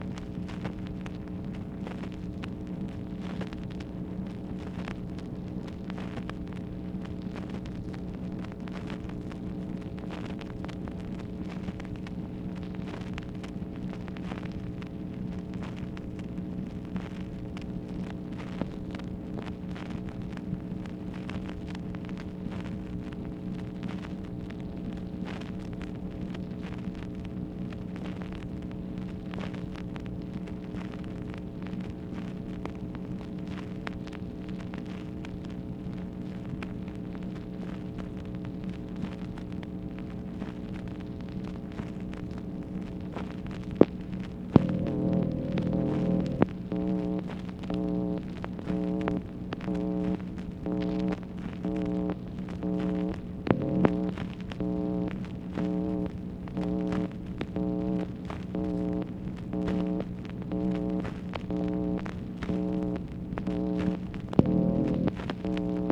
MACHINE NOISE, August 21, 1965
Secret White House Tapes | Lyndon B. Johnson Presidency